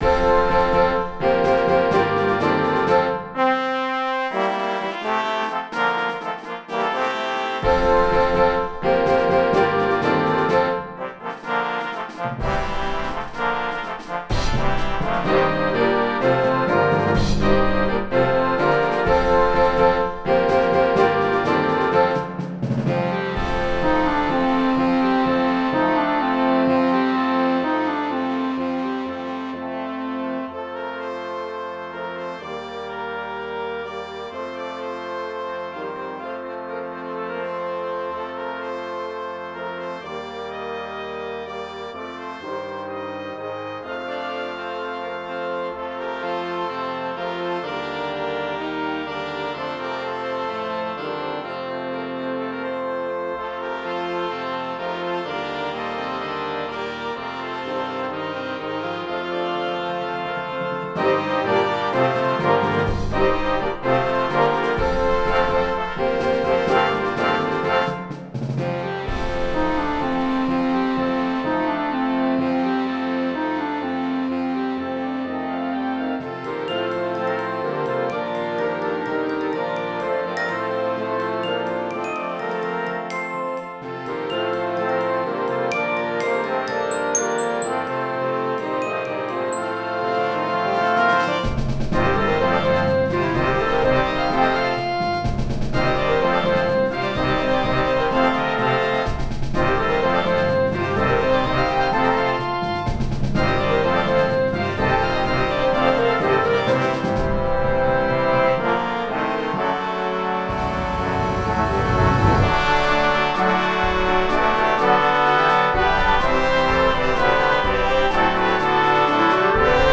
for concert Band